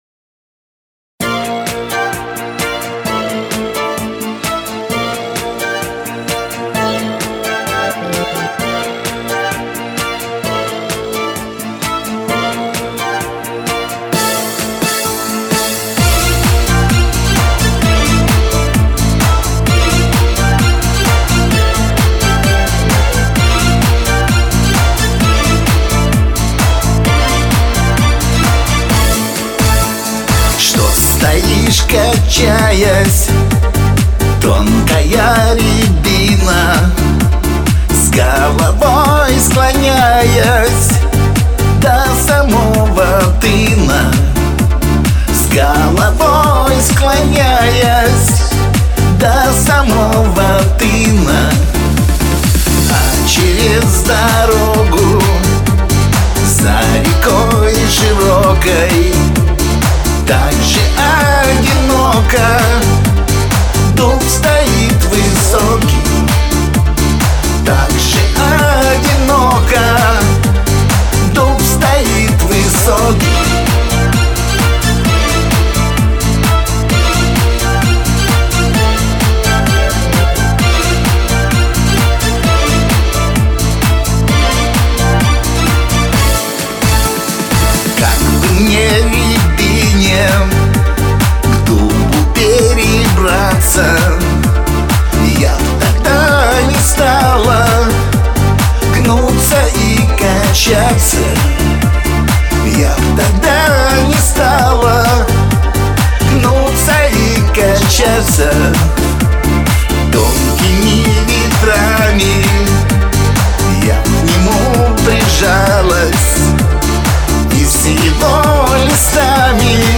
народная
Это же все-таки вполне лирическая песня, ее надо распевать.